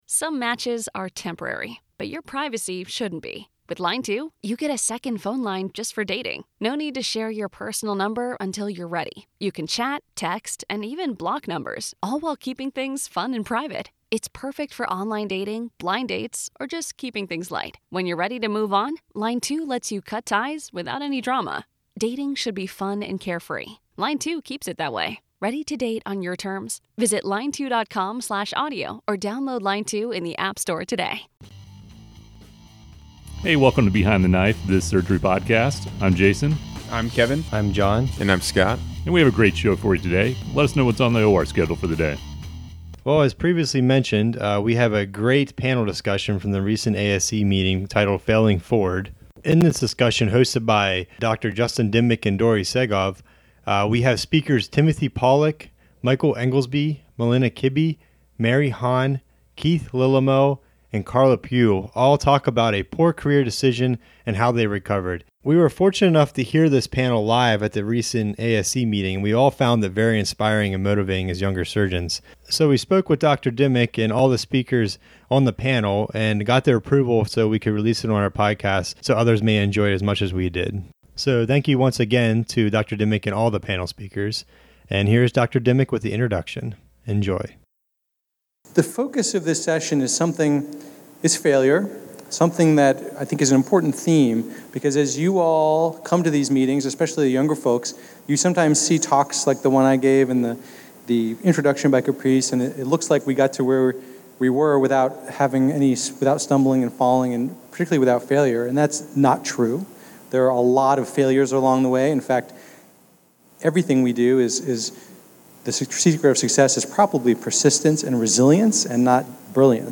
"Failing Forward" was a recently presented panel discussion at the Academic Surgical Congress.